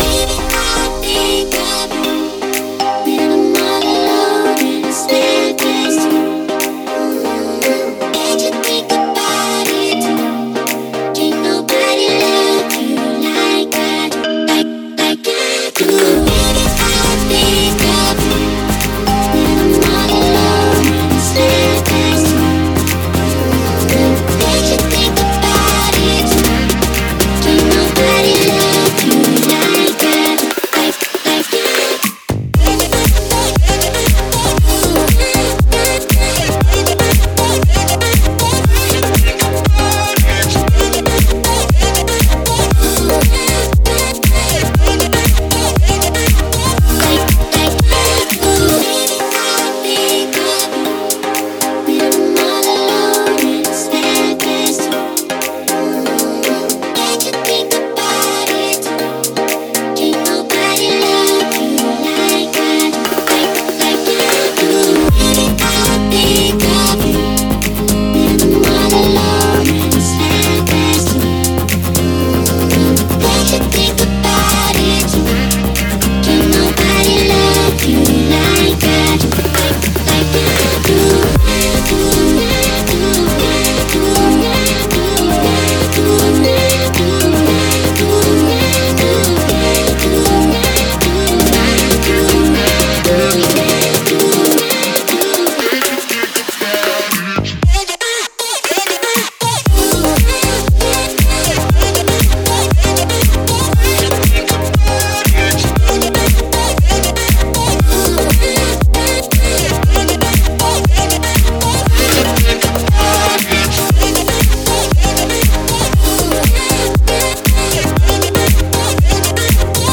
BPM59-118